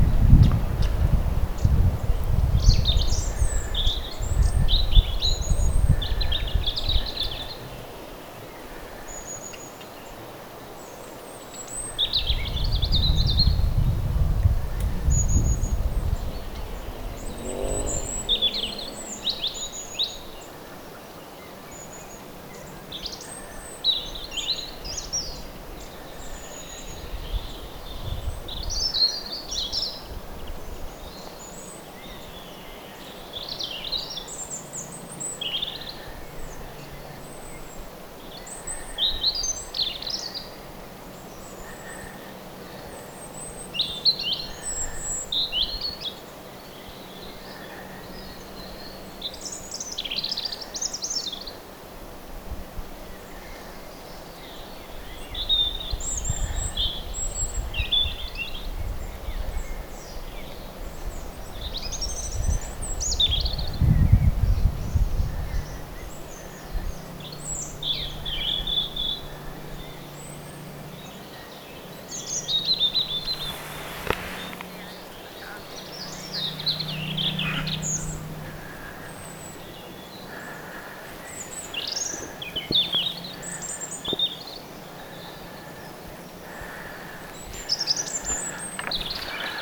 pyrstotiaispari_pienen_kahlaajarannan_pitkospuiden_risteyksessa.mp3